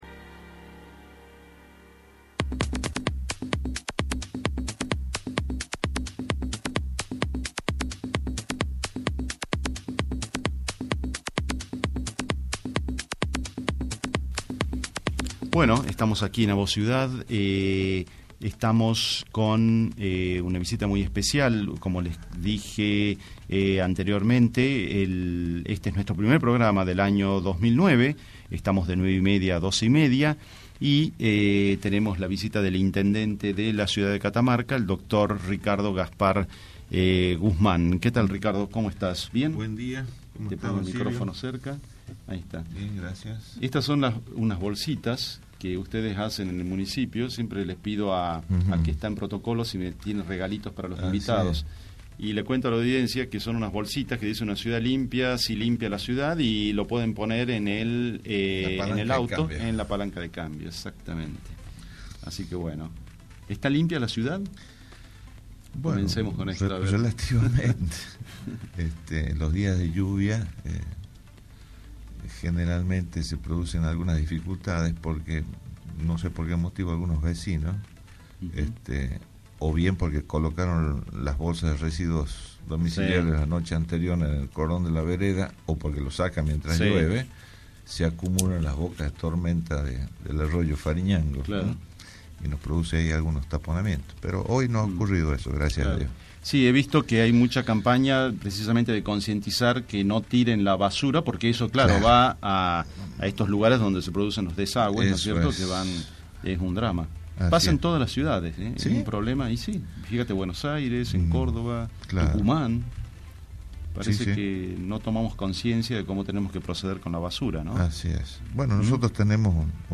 Descargar audio: Entrevista al intendente de SFVC